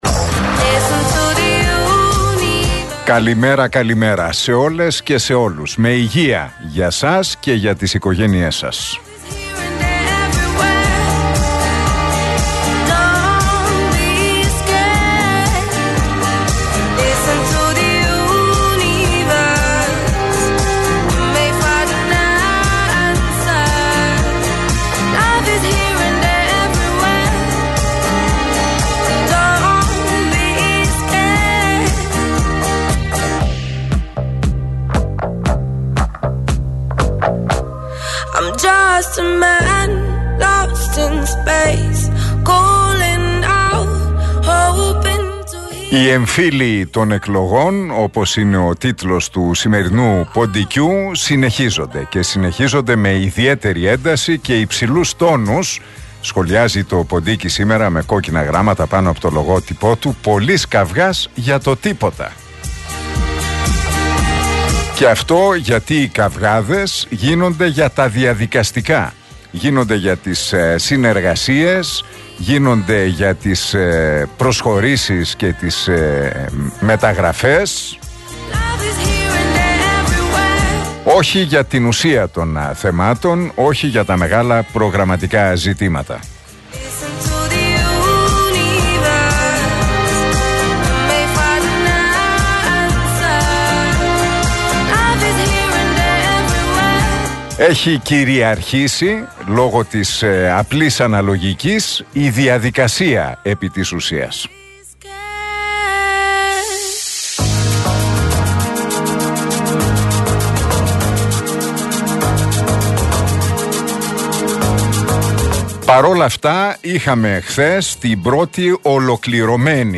Ακούστε το σχόλιο του Νίκου Χατζηνικολάου στον RealFm 97,8, την Πέμπτη 27 Απριλίου 2023.